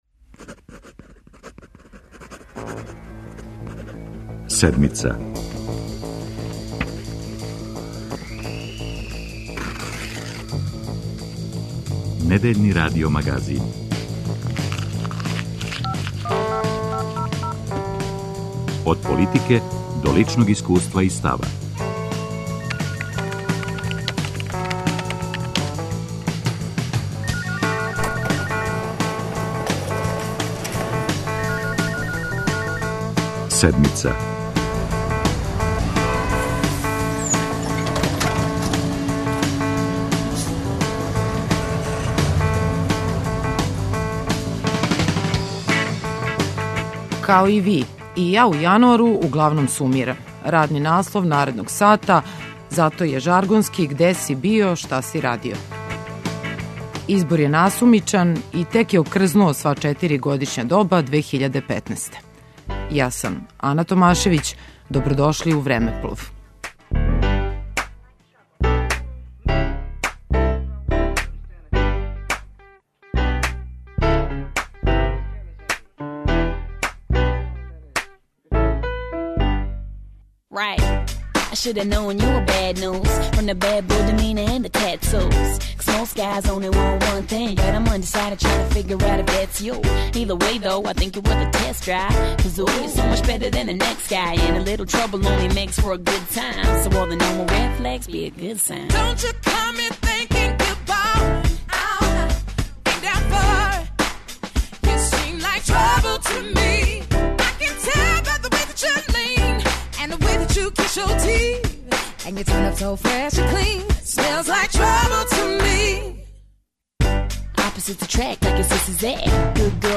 Преглед интервјуа из 2015. године